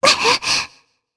Shea-Vox_Damage_jp_01.wav